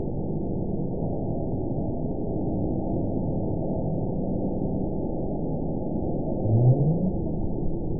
event 918187 date 07/29/23 time 17:11:03 GMT (1 year, 9 months ago) score 9.51 location TSS-AB03 detected by nrw target species NRW annotations +NRW Spectrogram: Frequency (kHz) vs. Time (s) audio not available .wav